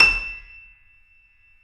Index of /90_sSampleCDs/Roland L-CD701/KEY_YC7 Piano ff/KEY_ff YC7 Mono